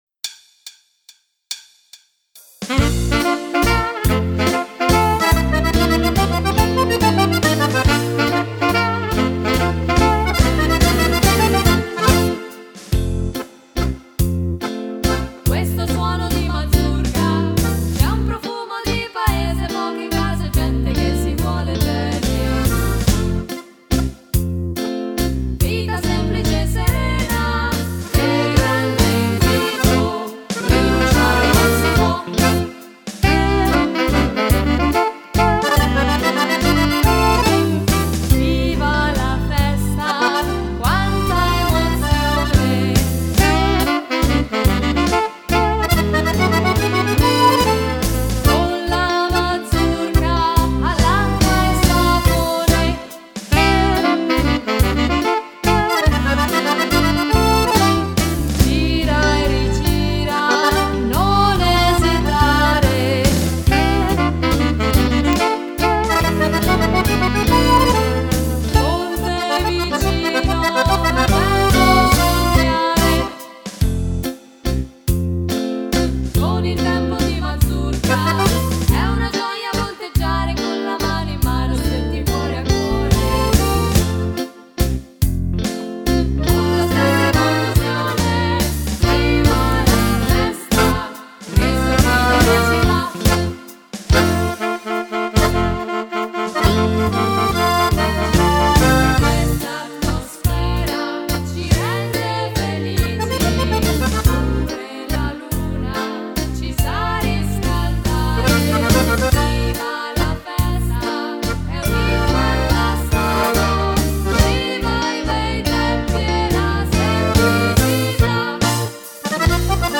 Mazurca
Uomo